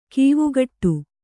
♪ kīvugaṭṭu